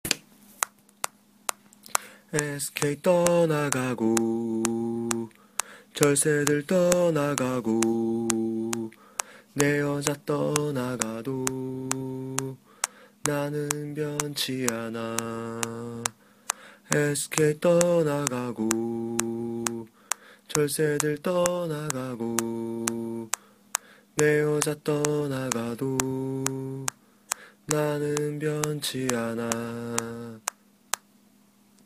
(야밤에 핸드폰 잡고 혼자 녹음 했으니 노약자 및 심장이 약하신 분들은 혼자 듣지 마시기 바랍니다)
<신규응원가>